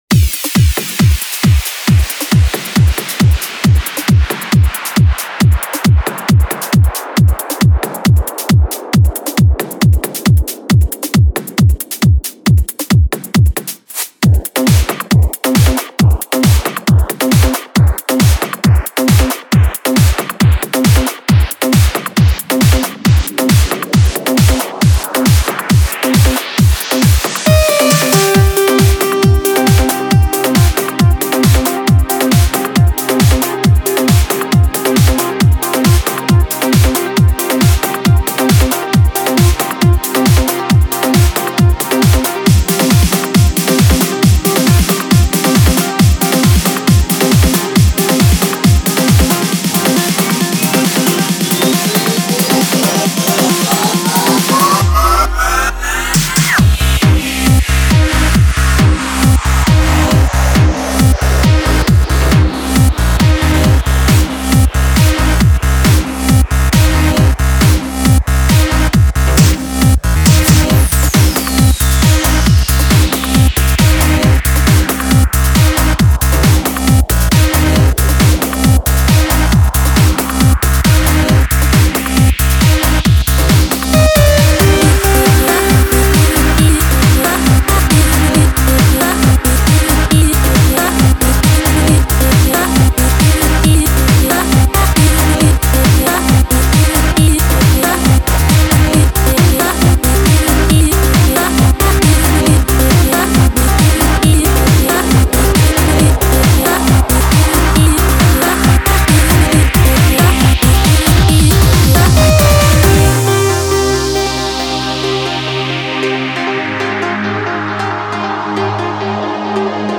Прошу оценить сведение (Progressive trance)